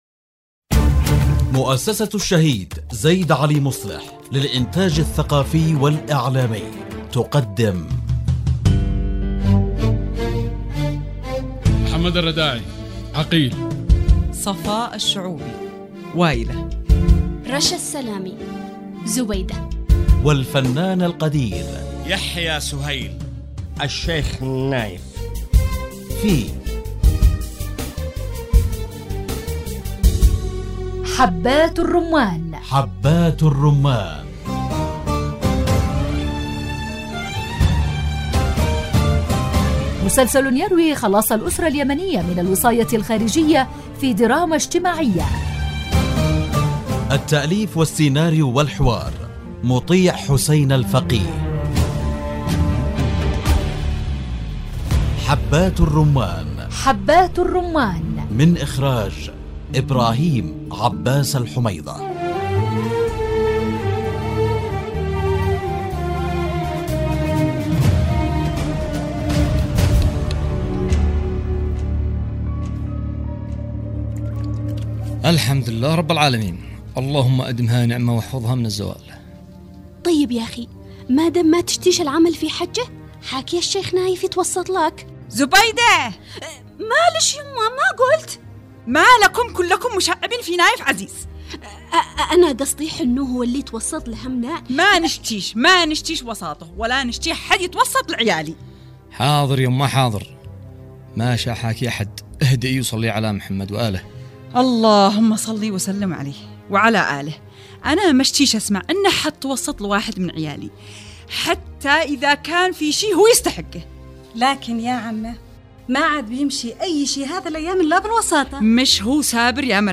مسلسل يحكي خلاص الأسرة اليمنية من الوصاية الخارجية في دراما اجتماعية مع ألمع نجوم الشاشة اليمنية